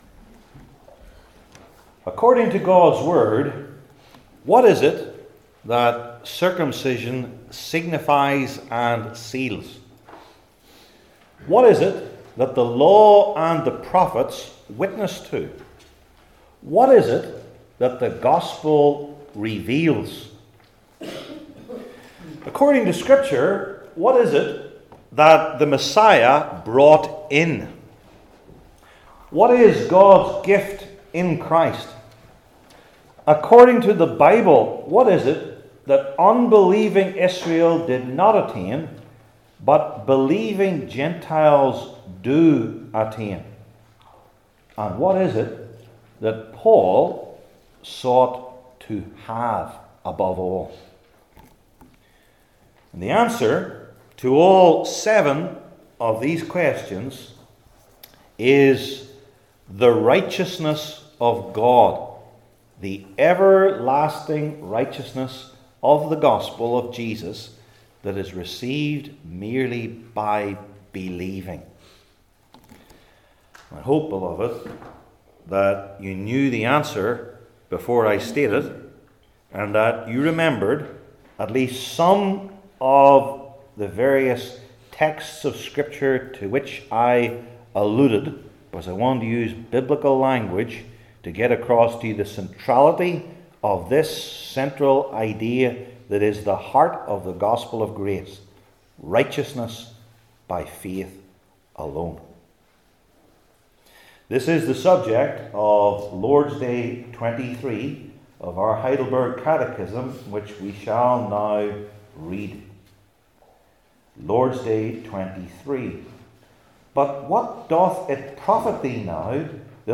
Heidelberg Catechism Sermons I. The Method of Teaching II.